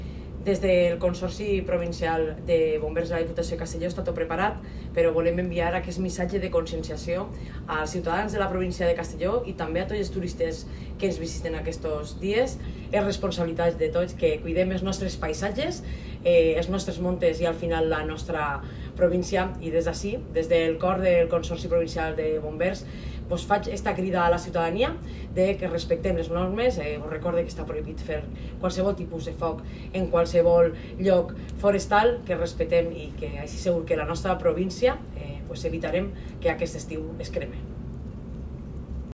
Corte-Audio-Presidenta-reunion-bomberos.wav